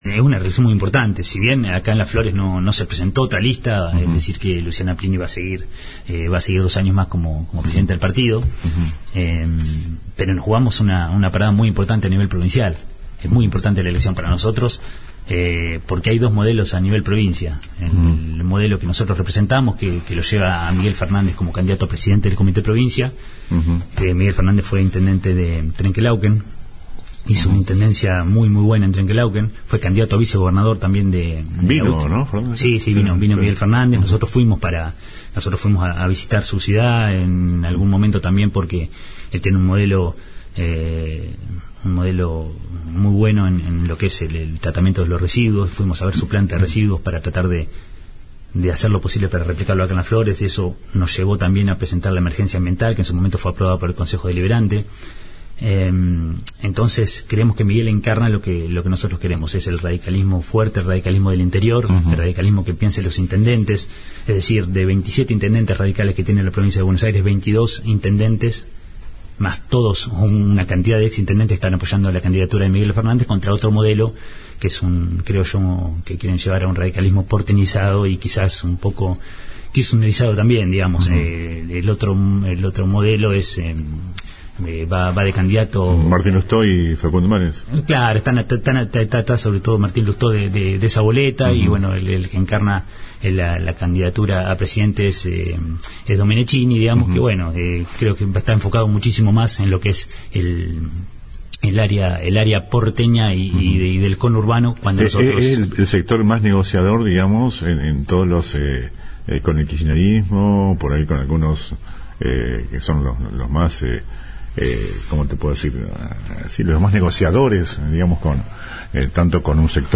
LA RADIO, entrevistó a distintos dirigentes por las internas del radicalismo que se dirime mañana domingo desde las 8 hs. hasta las 18 hs..
Por su lado, el concejal Sebastián Lizárraga contó en LA RADIO: